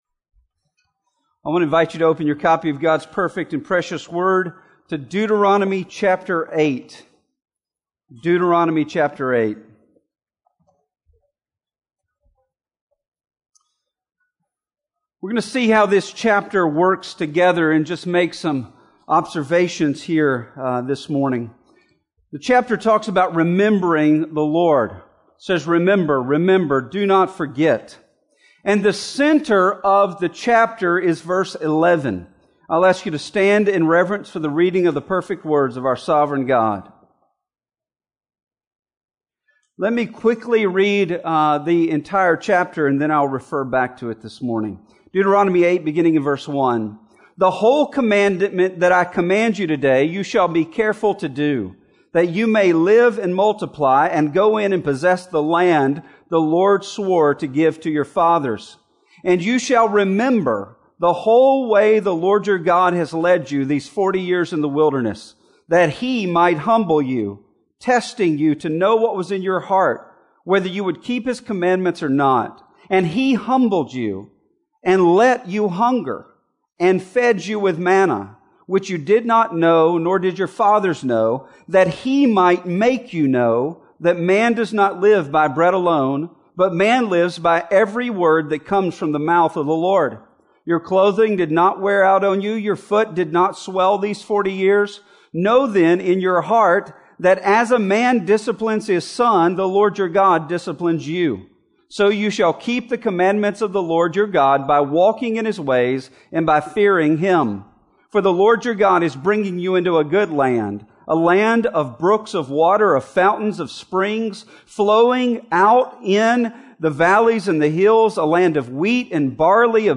Combined Campus Service Sermons (Deuteronomy 8 and Matthew 28:16-20)